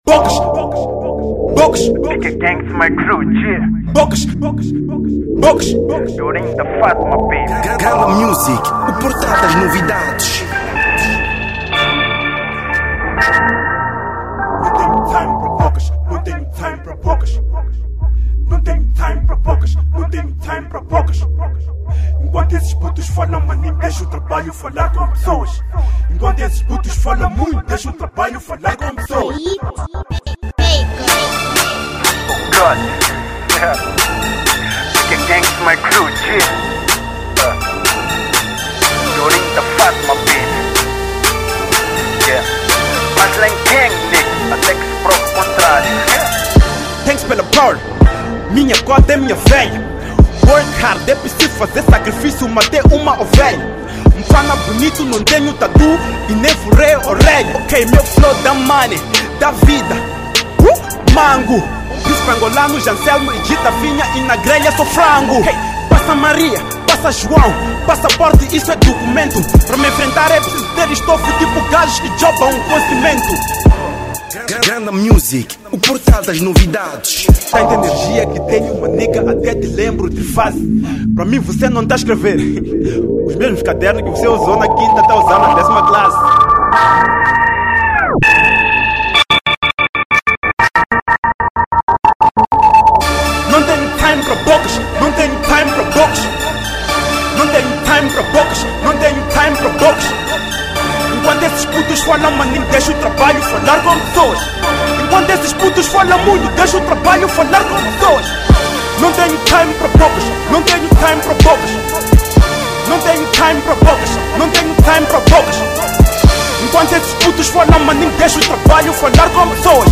00:03:00 | MP3 | 360bps |8MB | Rap